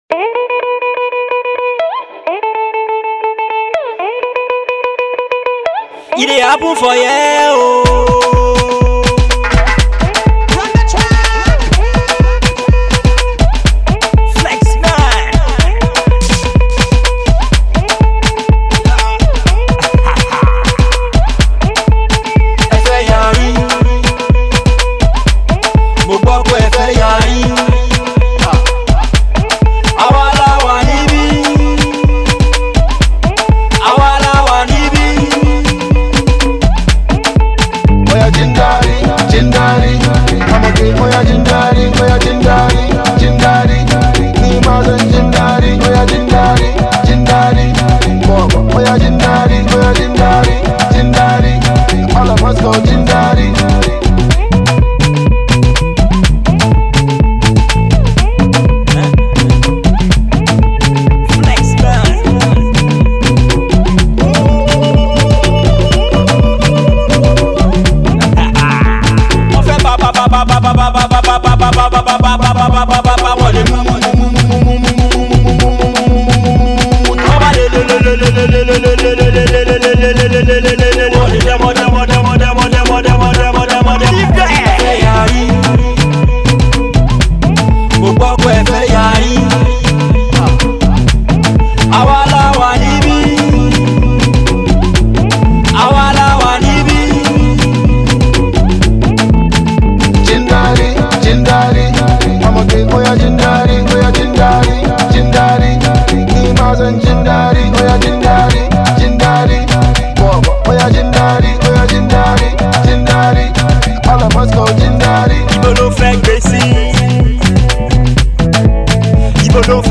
a Nigerian-US based Afrobeat icon
smooth vocal delivery